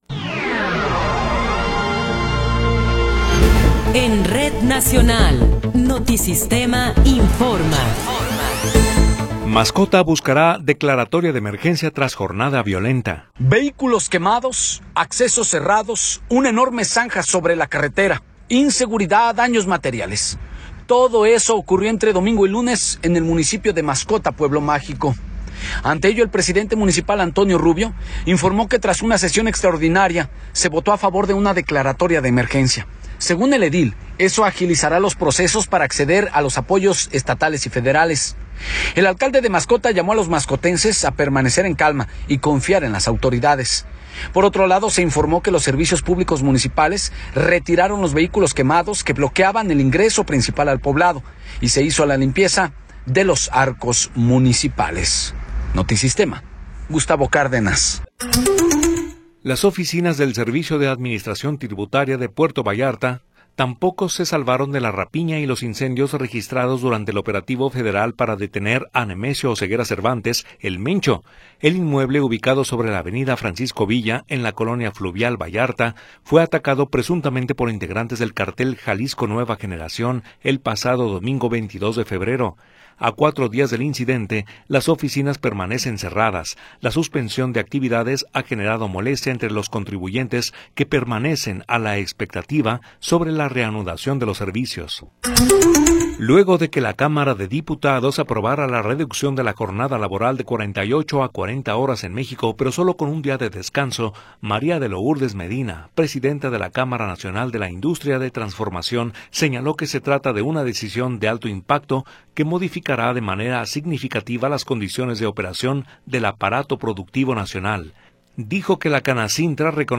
Noticiero 18 hrs. – 26 de Febrero de 2026
Resumen informativo Notisistema, la mejor y más completa información cada hora en la hora.